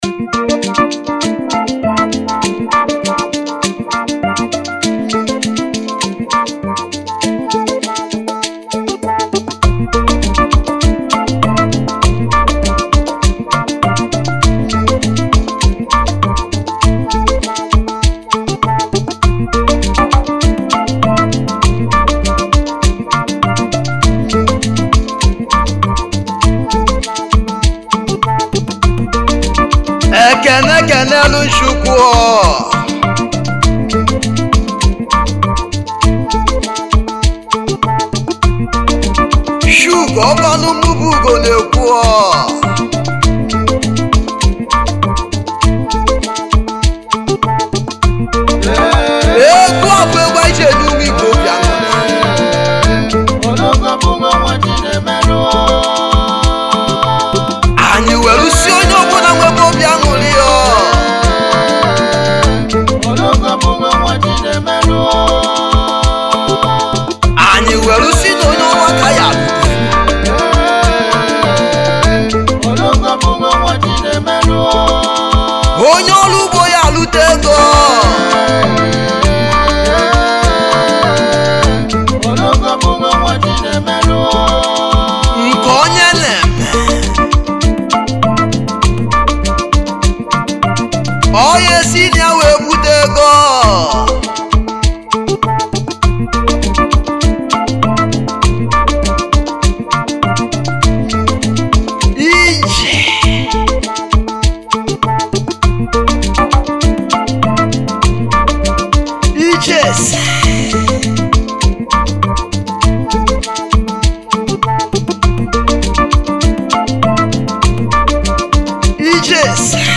Highlife Traditional Free